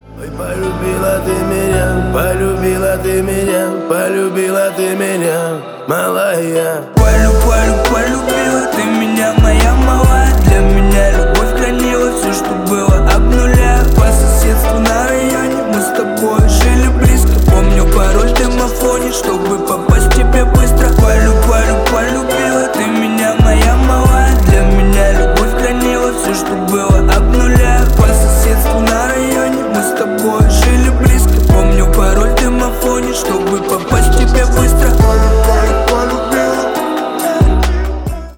Рэп и Хип Хоп
спокойные